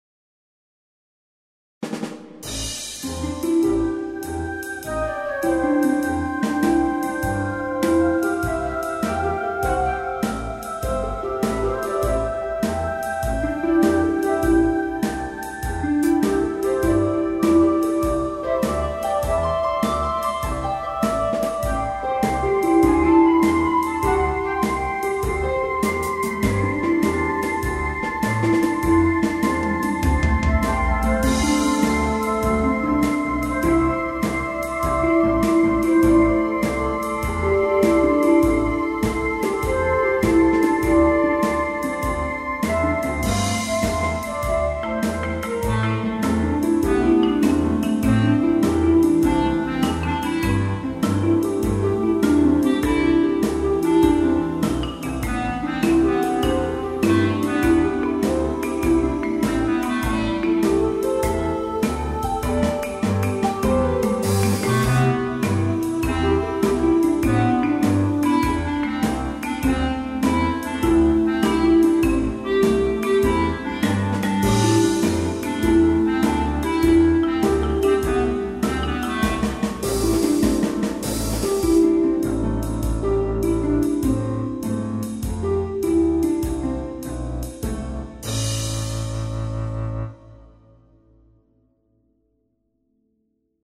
BGM
ショートジャズ